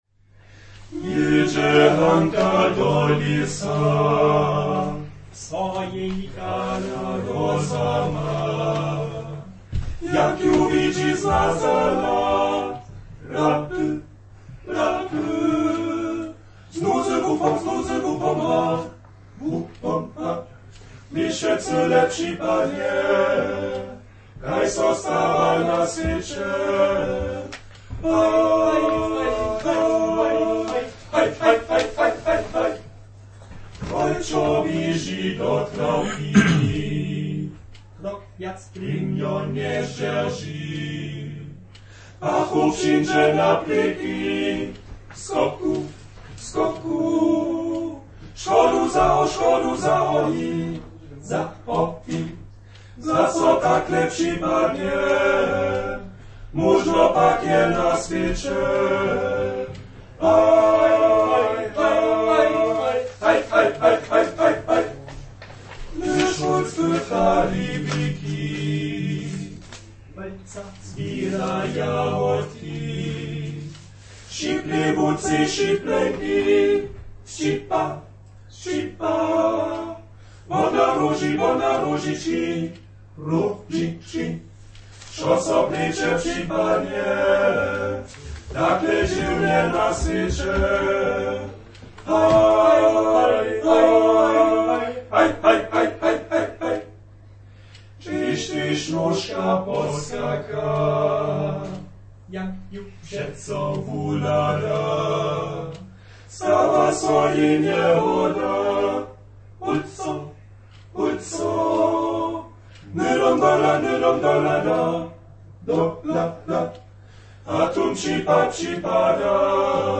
Namakaće tu prěnje fota a zwuki našeje sobotnišeje fejty.
„Po štyrjoch“ spěwachu mjez druhim tole: